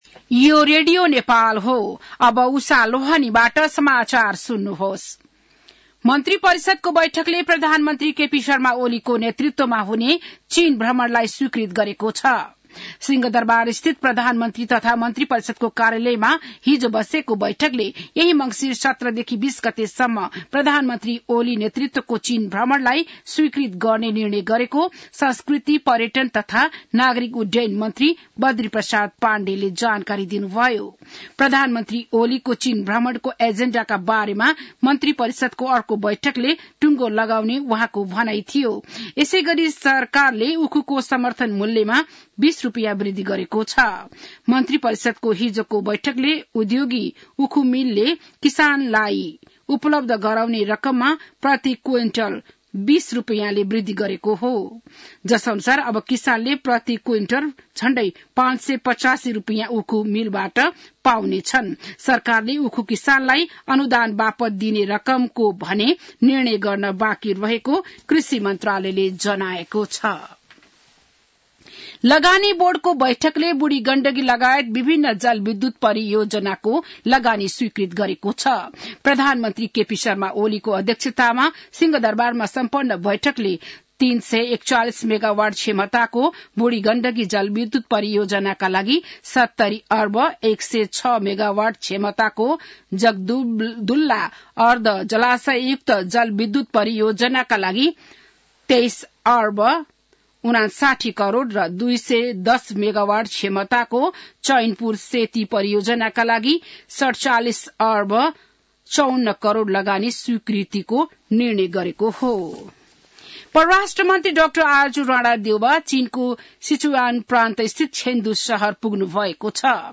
बिहान १० बजेको नेपाली समाचार : १५ मंसिर , २०८१